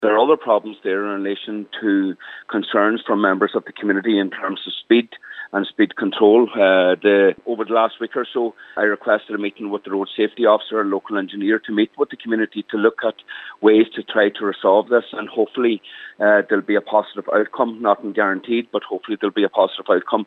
Cllr Mac Giolla Easbuig says the focus will now move on to addressing issues of speed in the area: